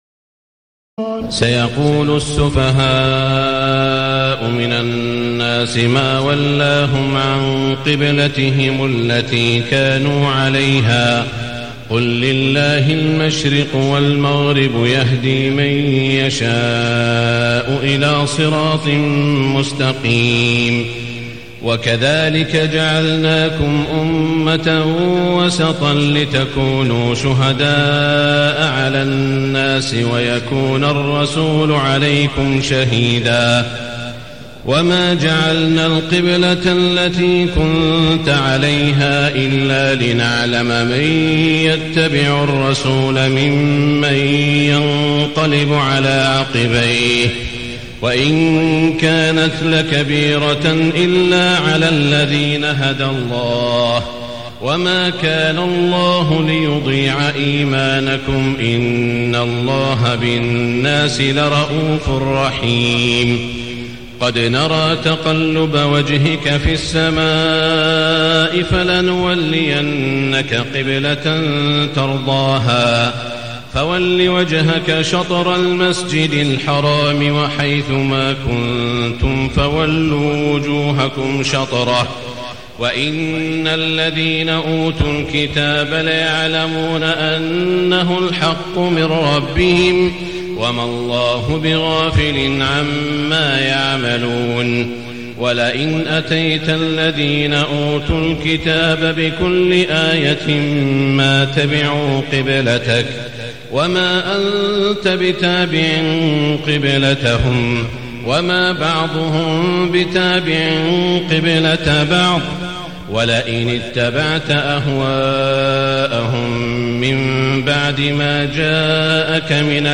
تهجد ليلة 22 رمضان 1437هـ من سورة البقرة (142-218) Tahajjud 22 st night Ramadan 1437H from Surah Al-Baqara > تراويح الحرم المكي عام 1437 🕋 > التراويح - تلاوات الحرمين